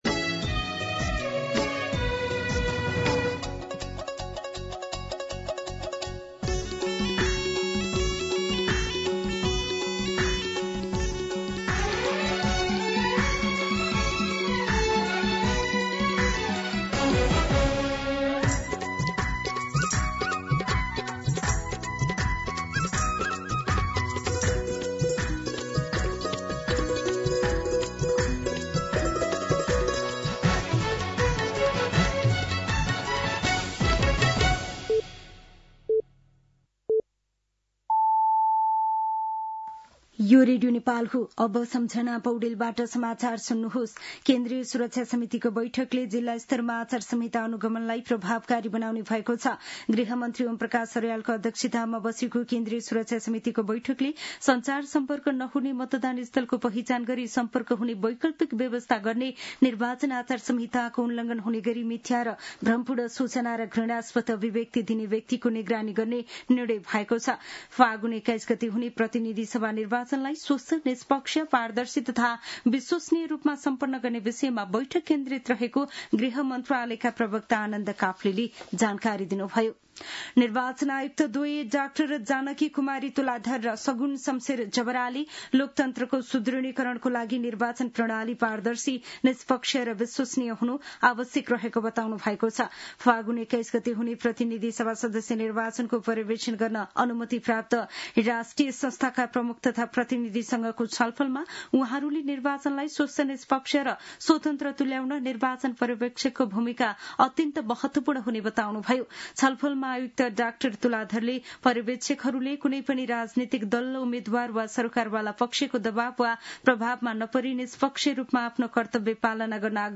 दिउँसो ४ बजेको नेपाली समाचार : २४ माघ , २०८२
4-pm-Nepali-News-1.mp3